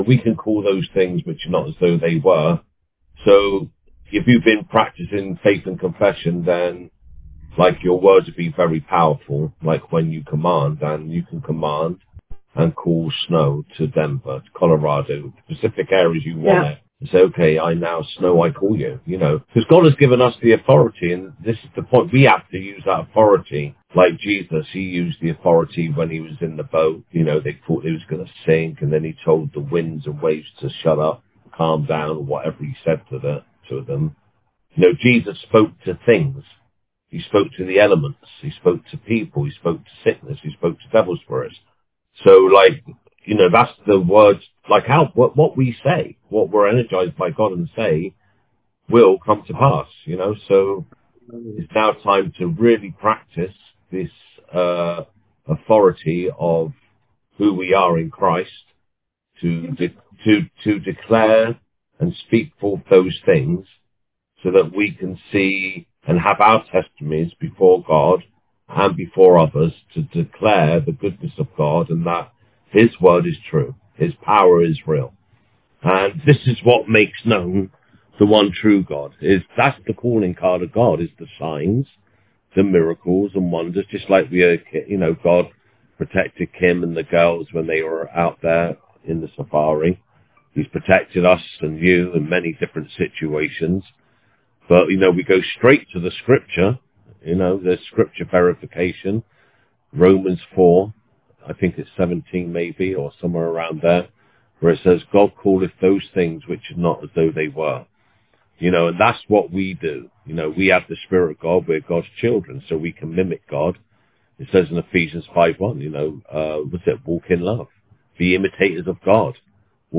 Now you can do the same as Jesus done. God wants you to use authority over sickness and disease, poverty, oppression and depression. with many other things encouraged in this audio taken from 18th January monthly leaders meetings 2026.